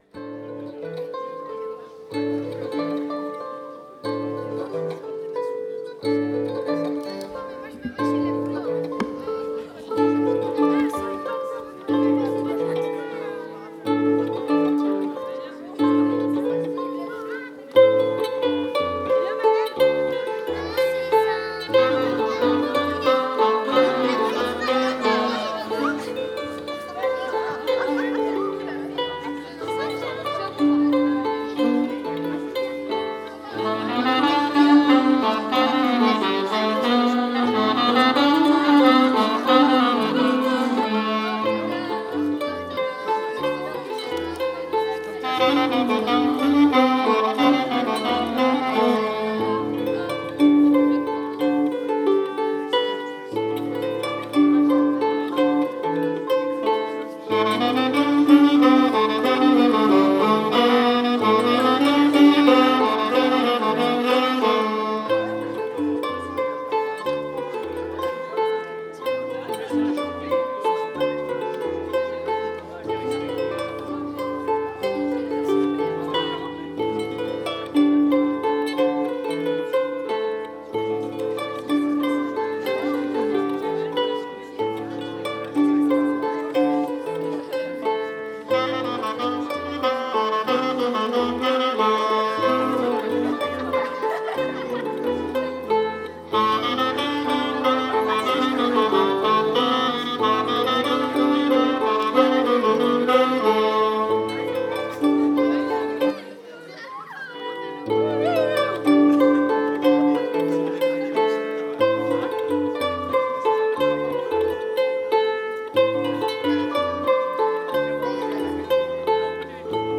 08_hanter_dros-harpes-clarinettes.mp3